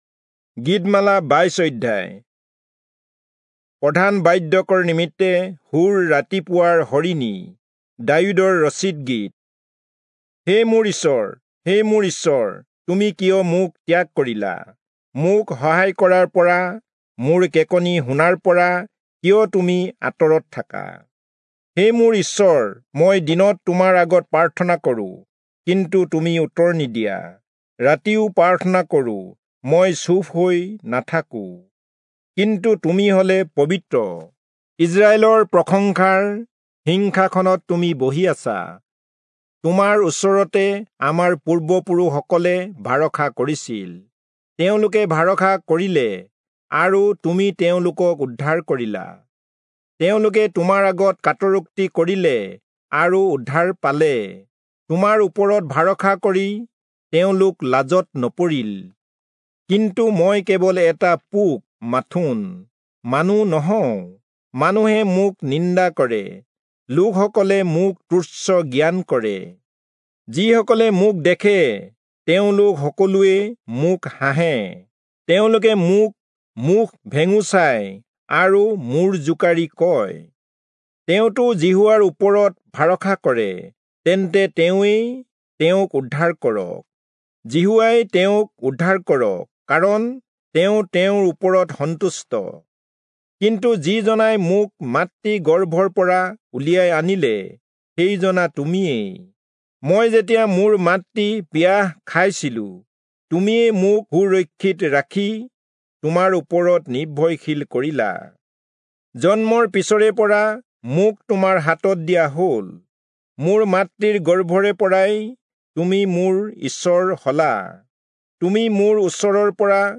Assamese Audio Bible - Psalms 55 in Orv bible version